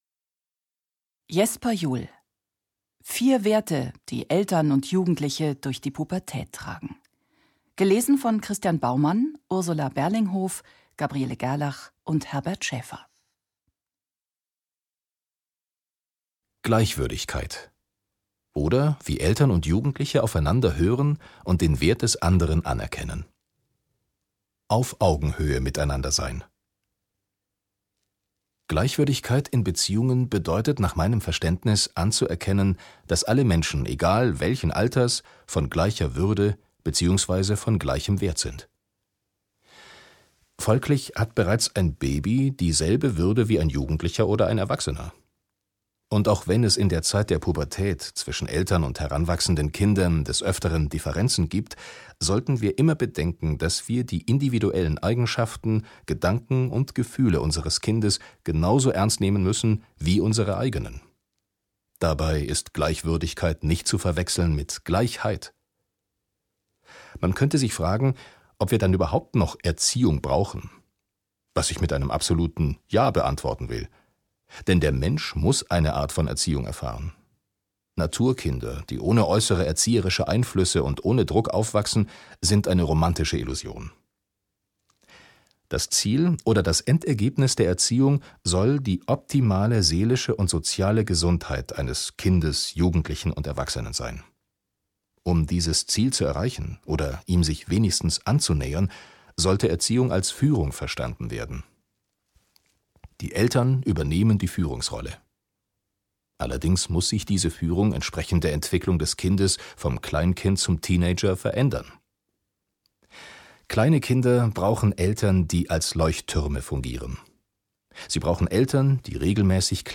Mit seiner kraftvollen und ausdrucksstarken Stimme